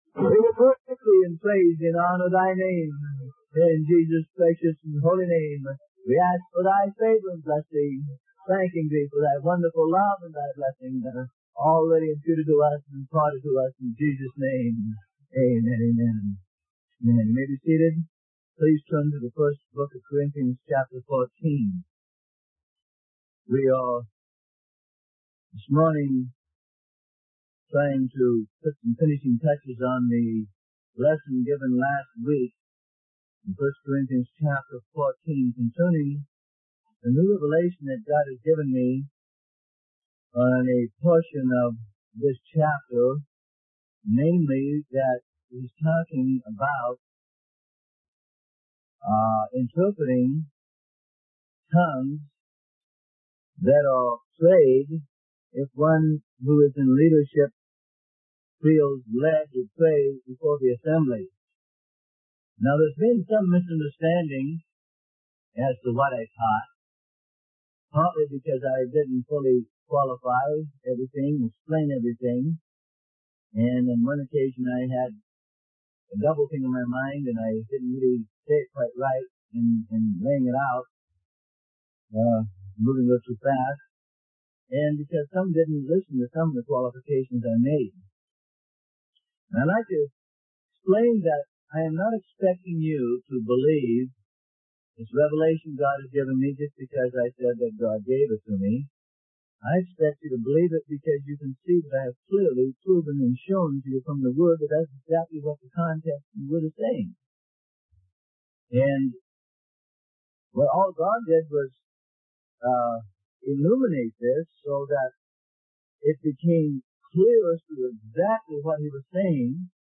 Sermon: Praying in Tongues and Interpretations - Part 2 - Freely Given Online Library